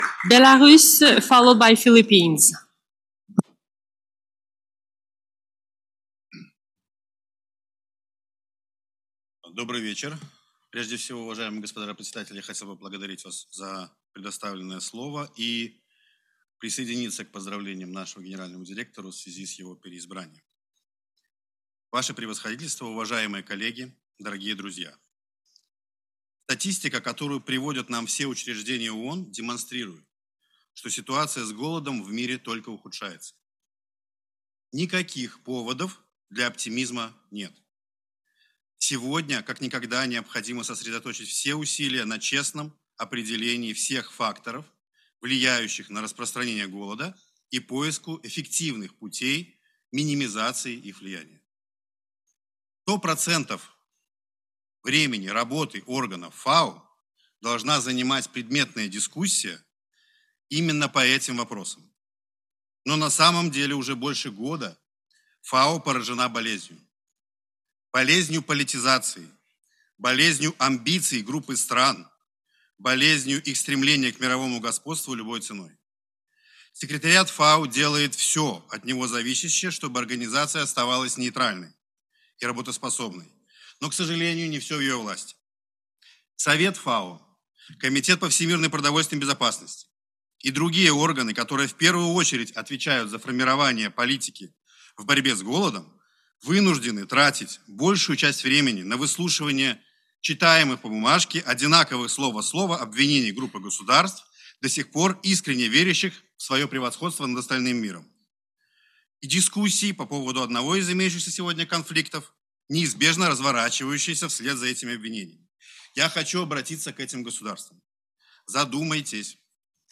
GENERAL DEBATE
Addresses and Statements
Kiryl Piatrouski, Alternate Permanent Representative
(Plenary –  Русский)